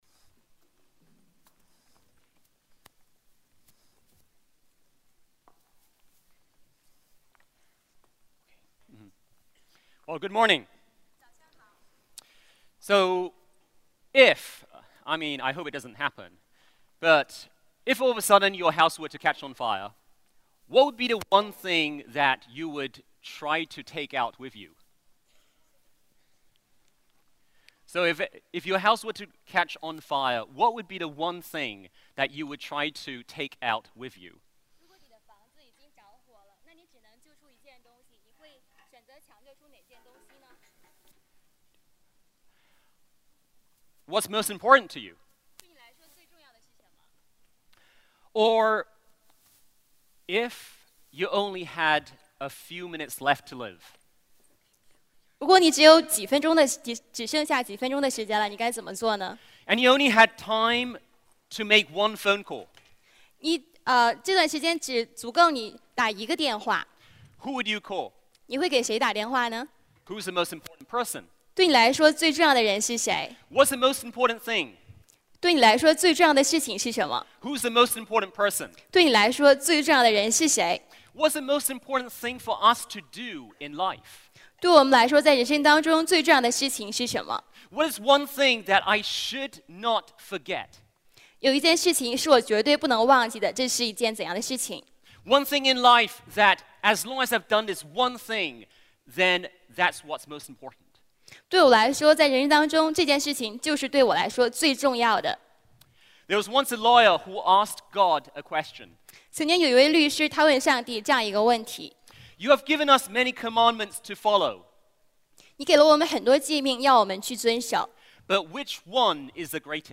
主日证道 |  最大的诫命